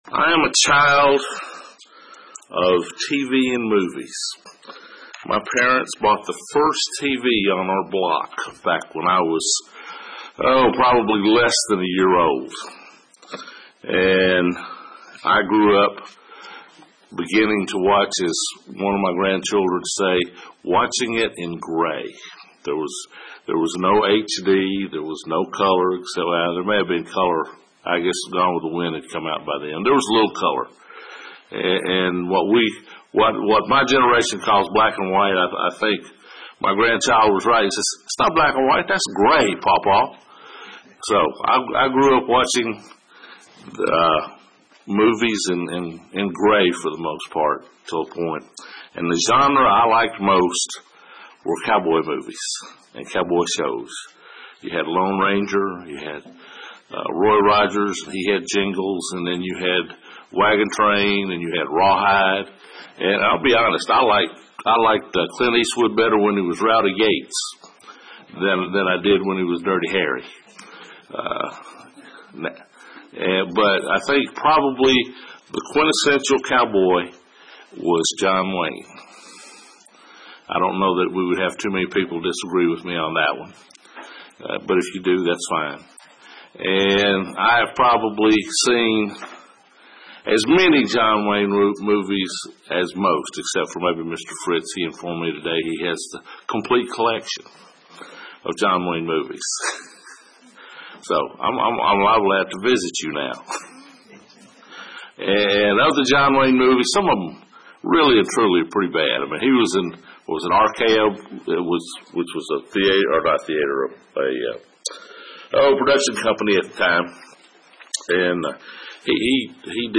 Jonathan is an often overlooked hero in the Bible. We'll examine exactly what that means in this sermon.
Given in Murfreesboro, TN